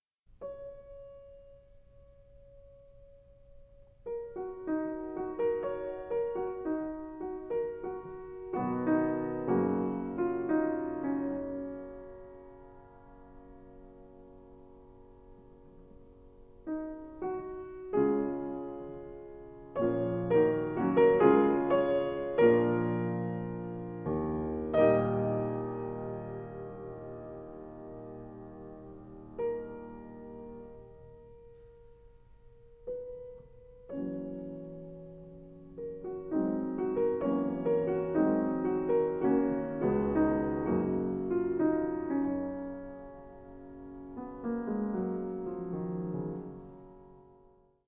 piano
Recorded 11-12 February 2014 at Philia Hall, Yokohama, Japan